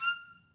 Clarinet
DCClar_stac_F5_v1_rr1_sum.wav